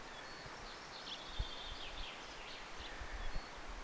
uoren isokäpylinnun kaksi ääntä??
arvaus_onko_nuoren_isokapylinnun_aania_kaksi.mp3